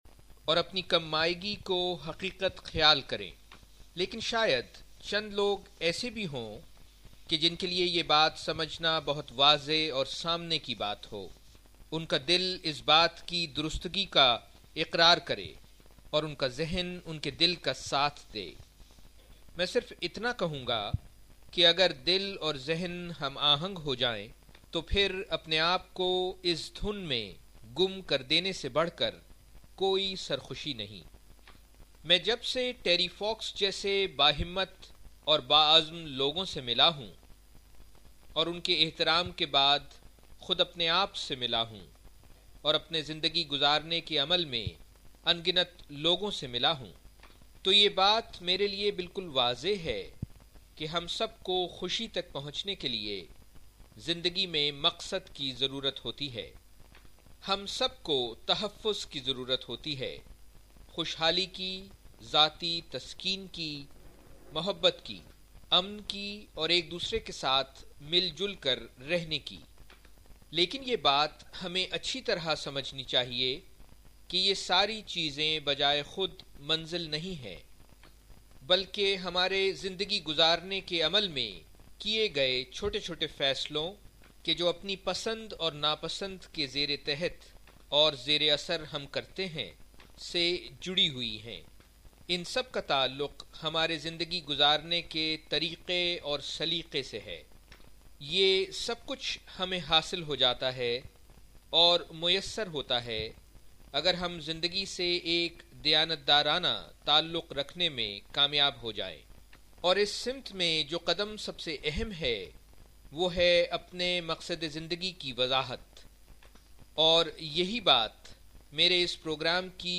An outstanding Urdu Podcast of a Leadership Workshop, the first of its kind. It takes its audience to the journey of self exploration while they learn to write their Mission Statement.